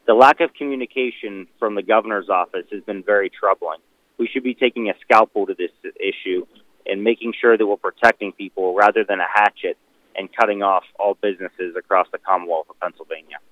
Bernstine says the governor’s actions are too harsh for businesses: